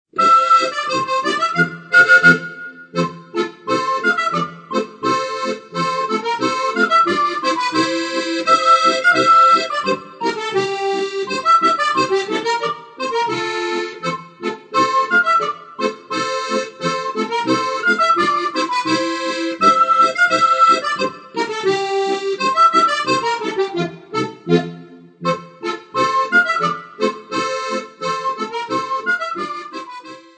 Besetzung: Okarina und Steirische Harmonika